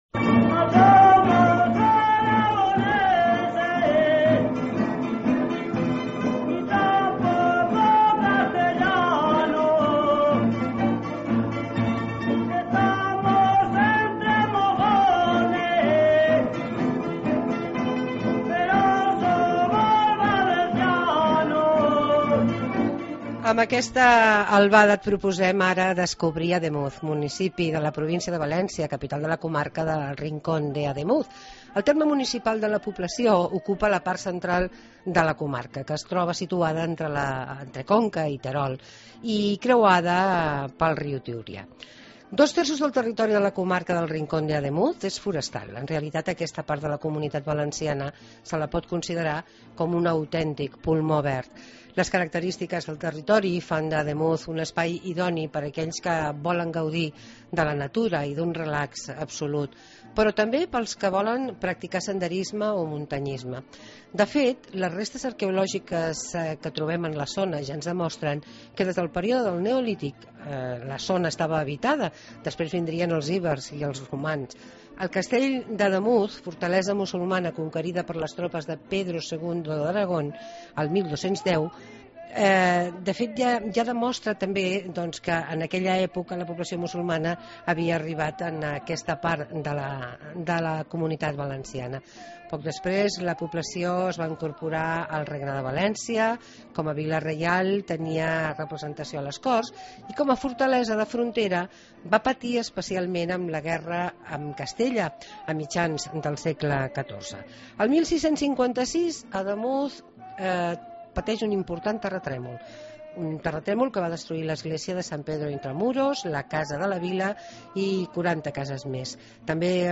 Conocemos Ademuz, en la provincia de Valencia, de manos de su alcalde Fernando Soriano